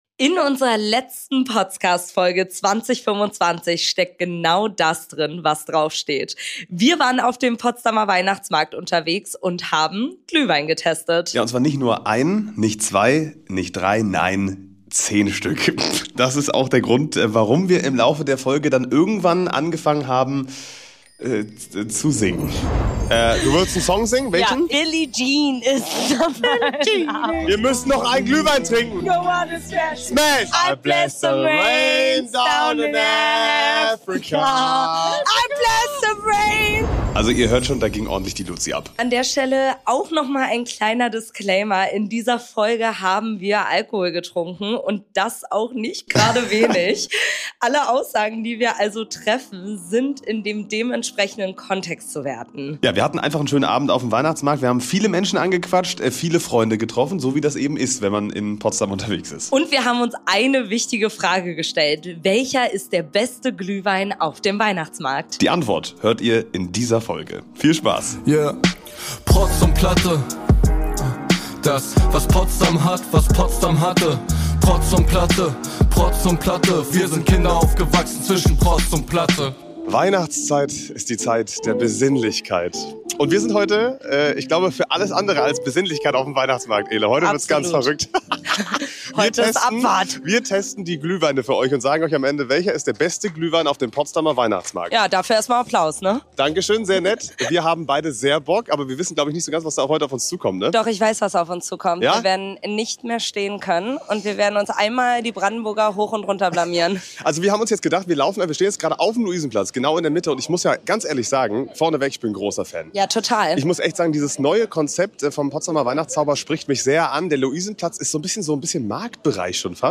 ON TOUR: Glühwein-Tour auf dem Potsdamer Weihnachtsmarkt ~ Der Potscast Podcast